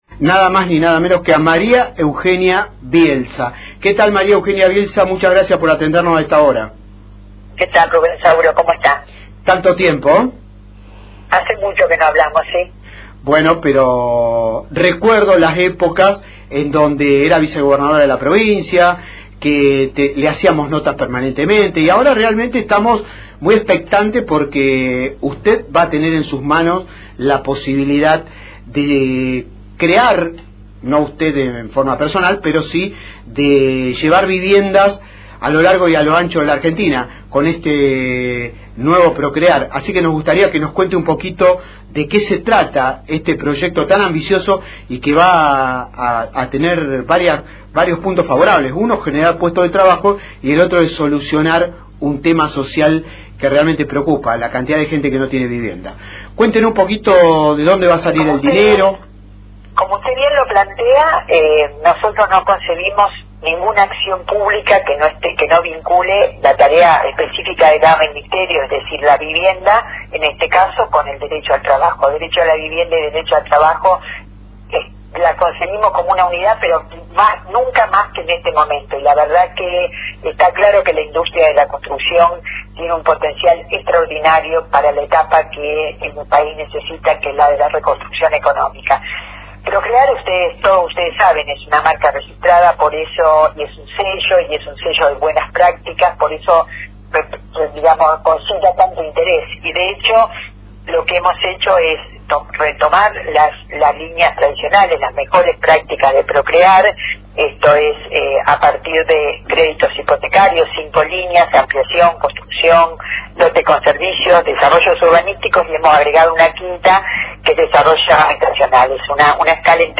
Entrevista completa a María Eugenia Bielsa en Futuro Regional, LT3:
Entrevista-Ma-E-Bielsa.mp3